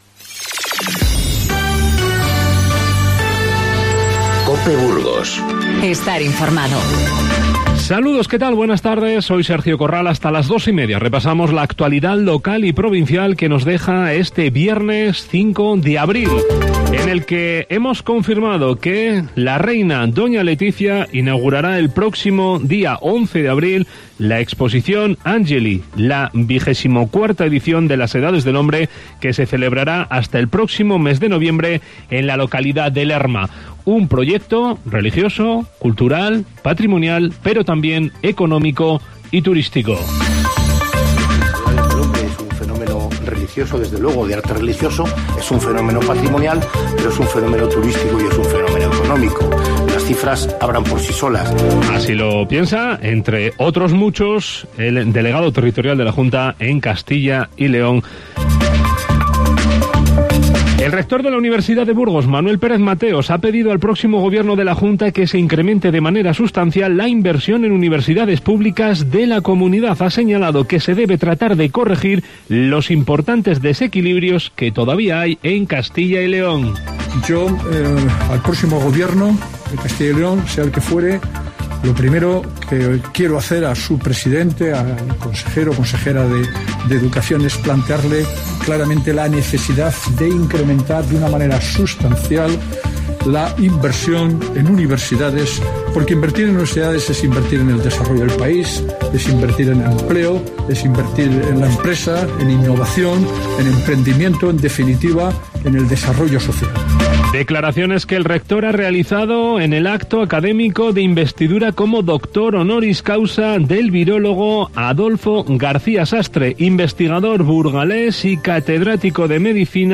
Informativo Mediodía COPE Burgos 05/04/19